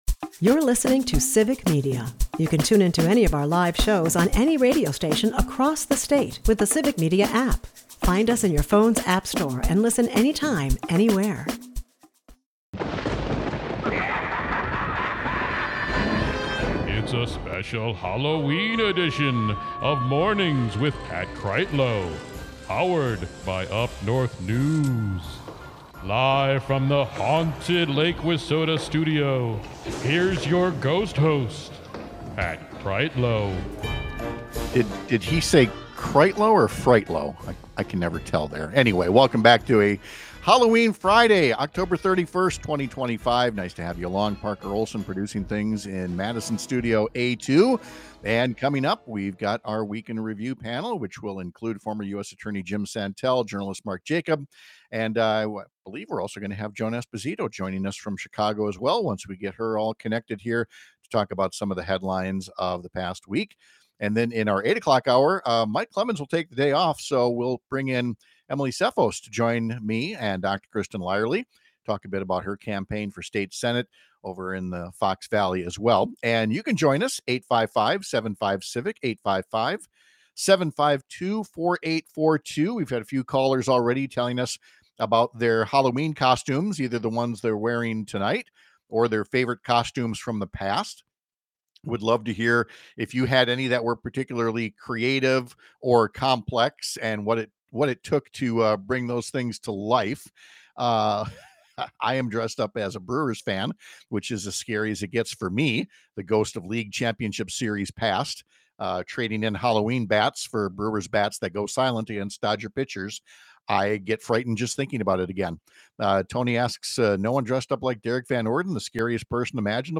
Our Week In Review panel will break down the stream of misinformation coming from the White House and Capitol Hill about the shutdown and the pain being needlessly inflicted on American families and the nation’s economy.